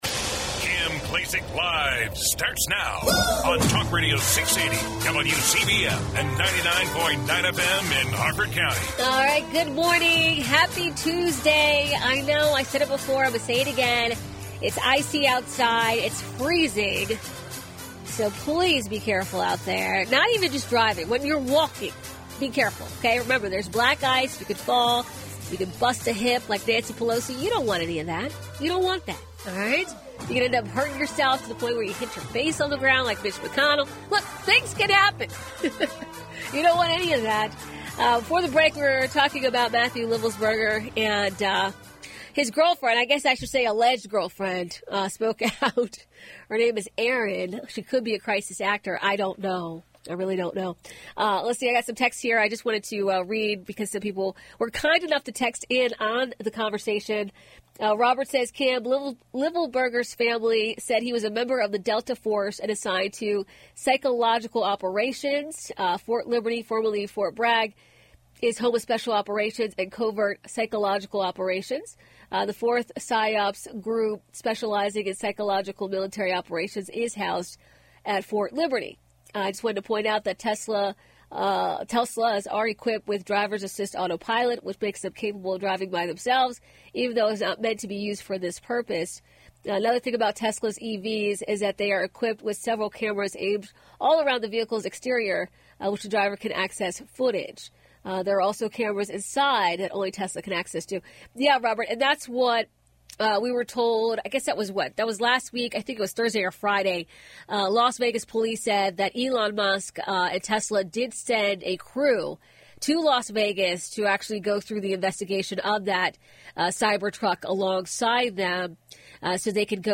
Kim Klacik is a dynamic voice who isn’t afraid to speak her mind.
Don’t miss your chance to hear from the one and only Kim Klacik live on WCBM weekdays from 9am to noon.